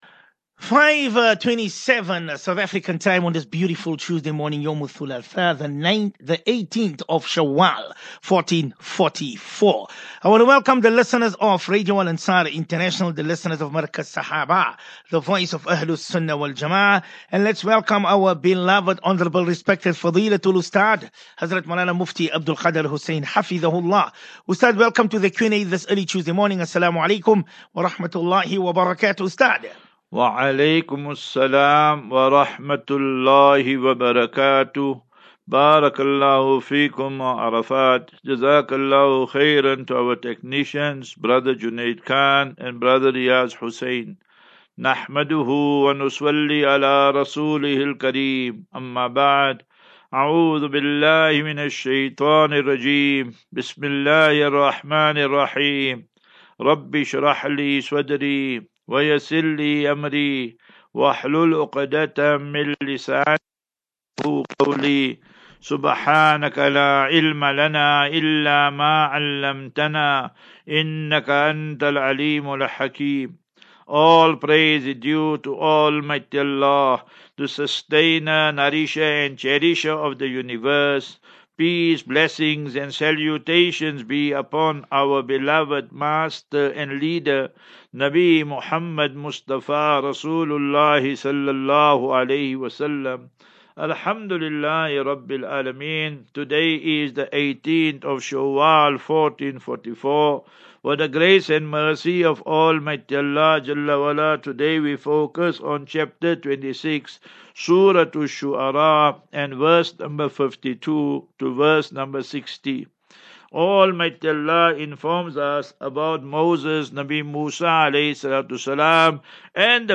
As Safinatu Ilal Jannah Naseeha and Q and A 9 May 09 May 23 Assafinatu